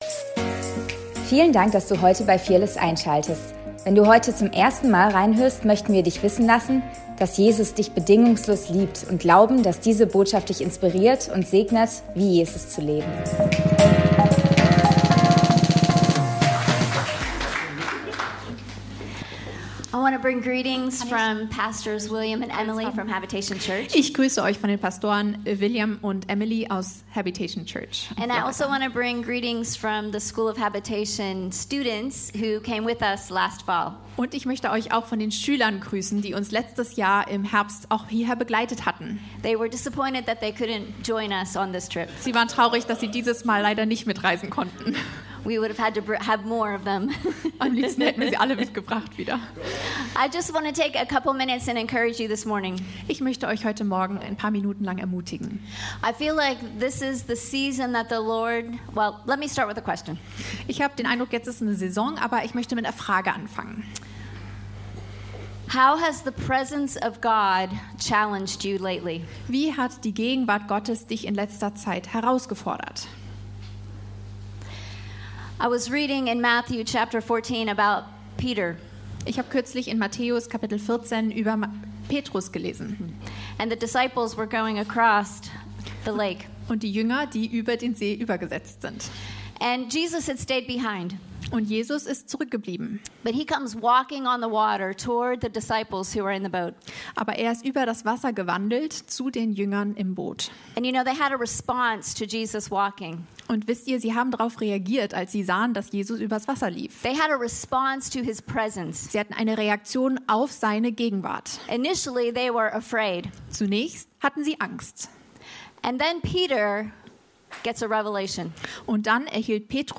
Fearless Church - Predigt vom 15.03.2026